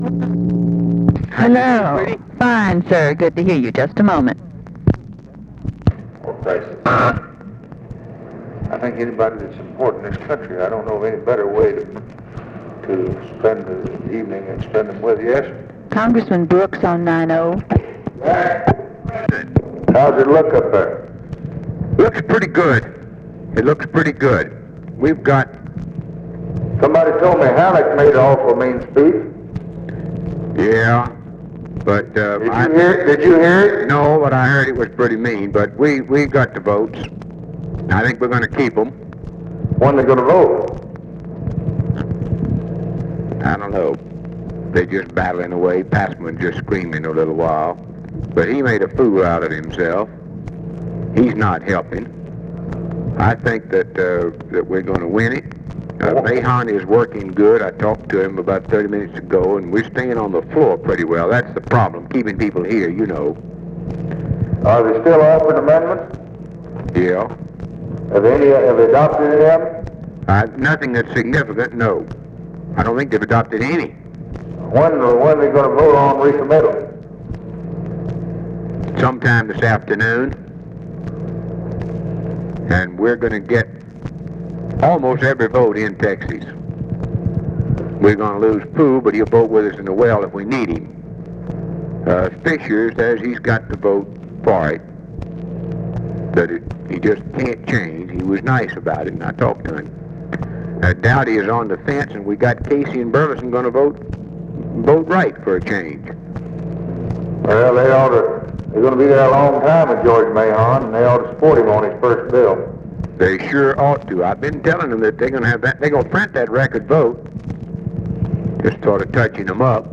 Conversation with JACK BROOKS and OFFICE CONVERSATION, July 1, 1964
Secret White House Tapes